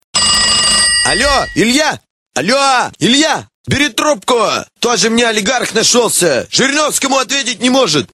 Прикольные рингтоны